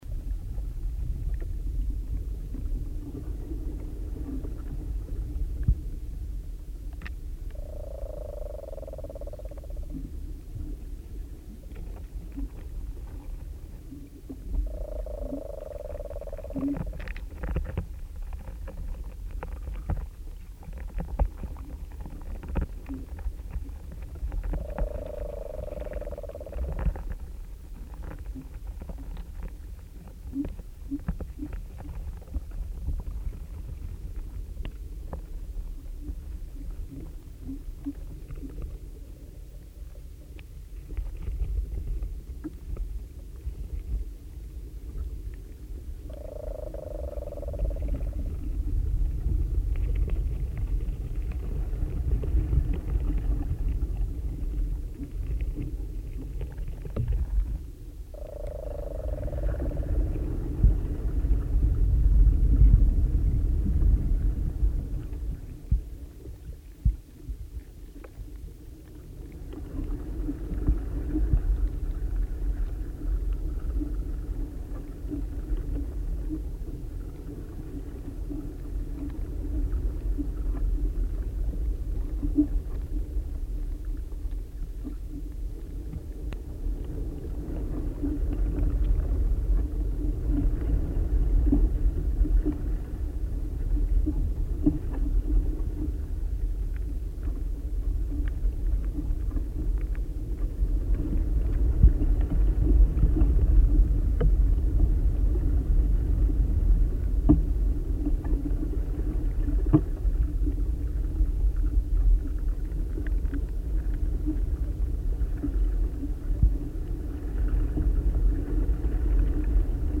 We have been making a series of field recordings of trees.
More recently we have been using contact mics on the trunks of the trees to hear the sound of the rising sap in the xylem tubes. The recordings reveal surprising sloshing and guzzling sounds, as well as knocks, creaks and groans within the timber.
Recordings using two contact mics on the trunk
Small oak in a field